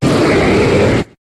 Cri de Latios dans Pokémon HOME.